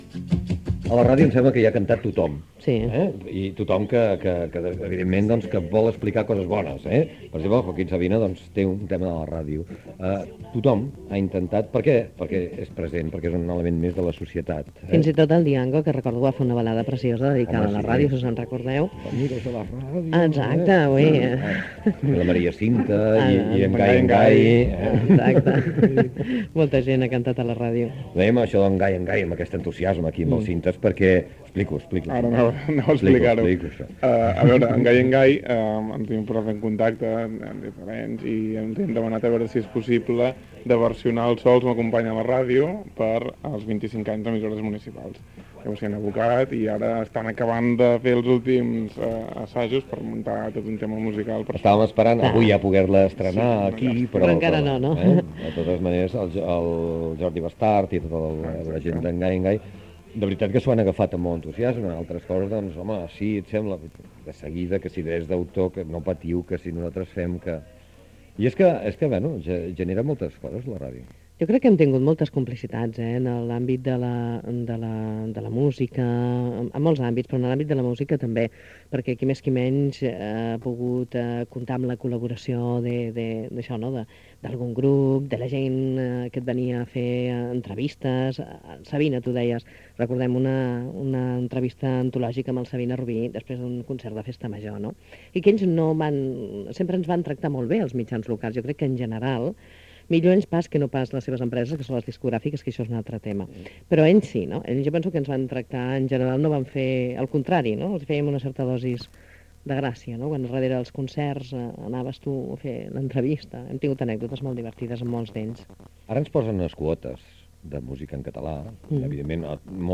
Fragment de la tertúlia